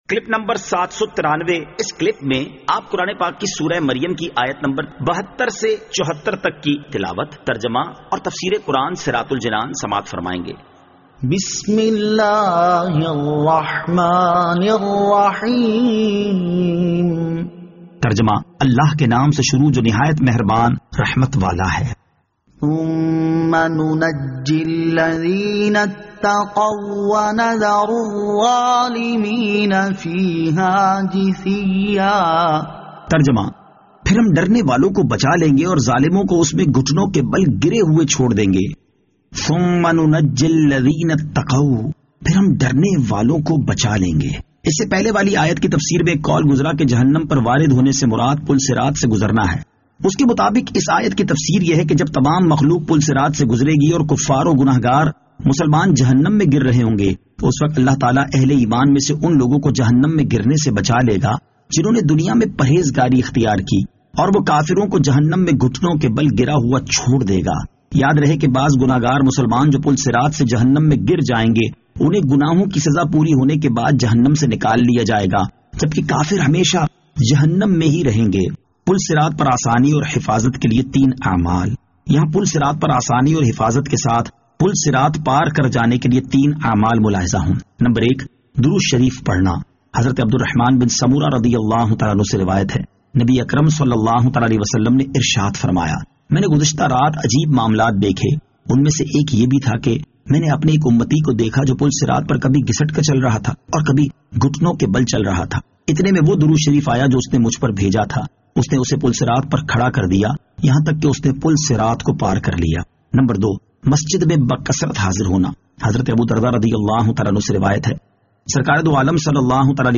Surah Maryam Ayat 72 To 74 Tilawat , Tarjama , Tafseer